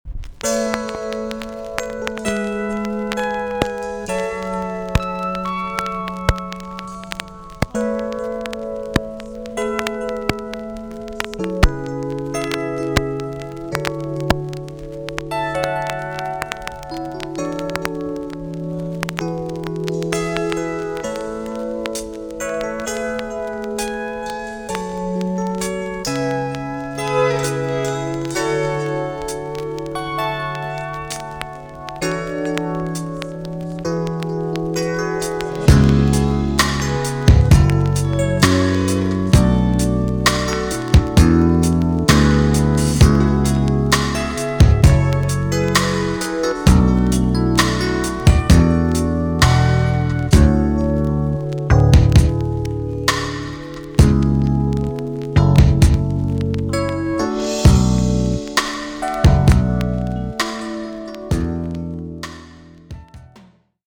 B.SIDE INST
VG~VG ok 軽いチリノイズと前半にプチノイズがあります。